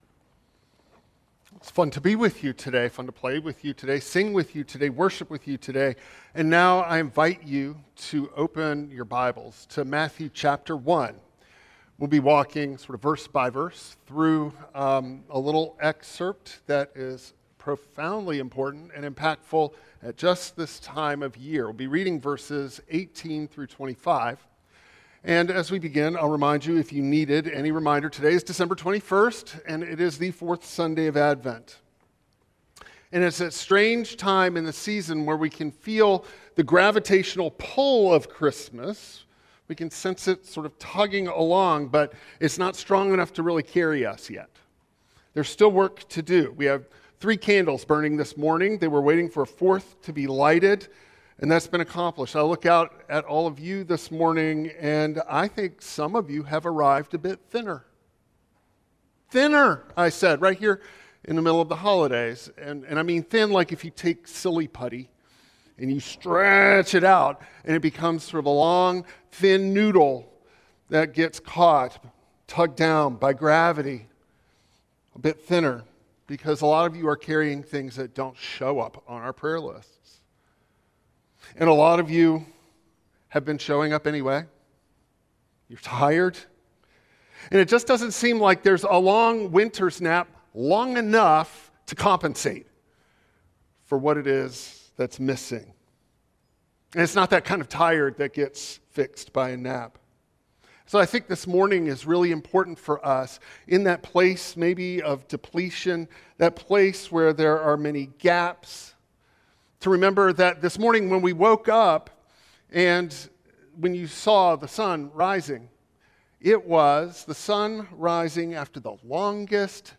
Advent IV sermon on Matthew 1:18–25 exploring Joseph’s quiet righteousness, faithful love, and the church’s call to bear hearthlight, not a spotlight.